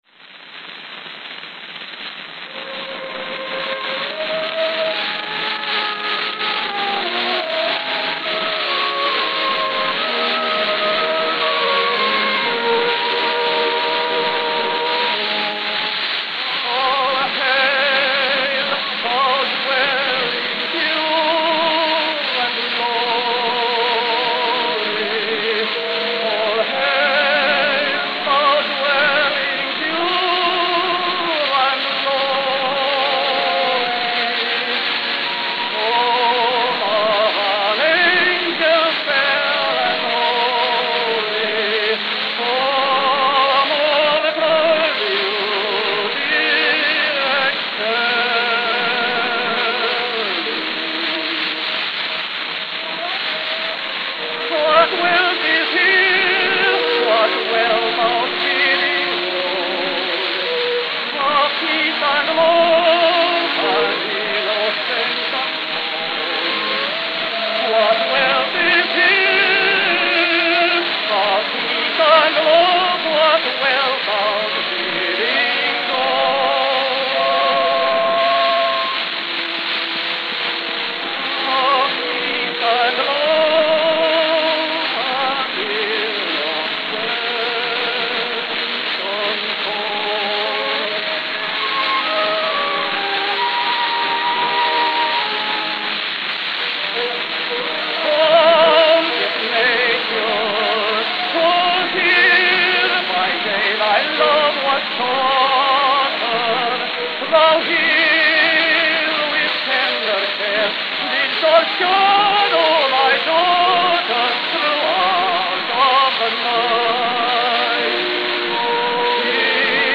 Note: Worn but no skips.